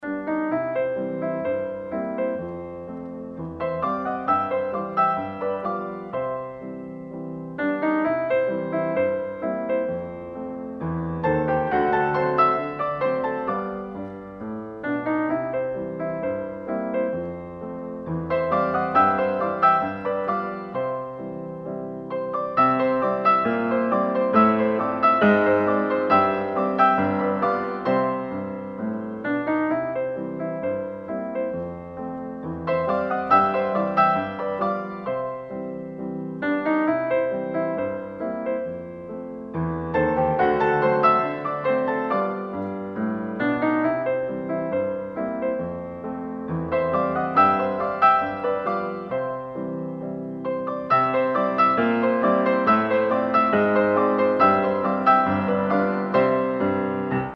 • Качество: 192, Stereo
классика
рояль
Прекрасный рингтон классической музыки